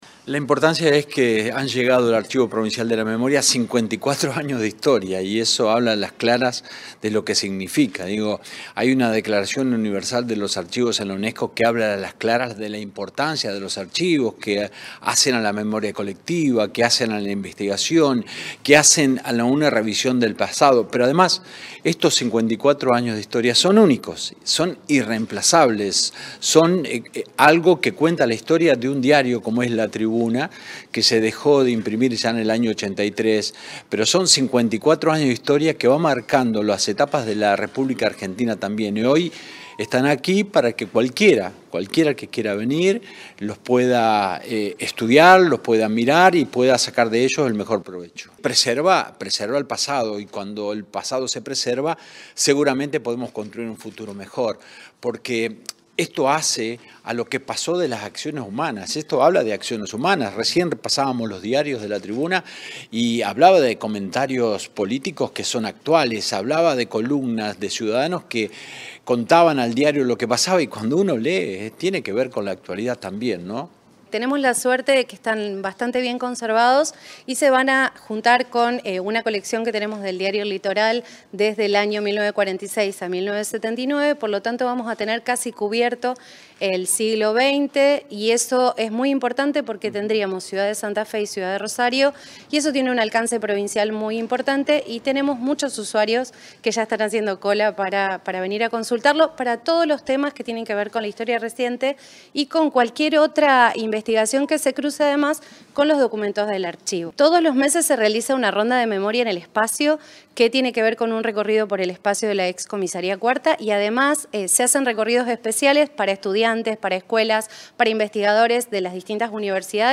En el edificio de la ex Comisaría 4ª, ubicado en calle Tucumán 3489 de la ciudad de Santa Fe, sede del Archivo Provincial de la Memoria, el secretario de Derechos Humanos, Emilio Jatón, brindó los detalles a los medios de prensa.